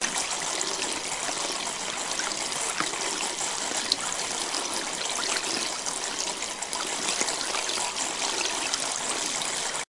桶装水
描述：中式浴室的浴缸填充物
Tag: 浴室 灌装 浴缸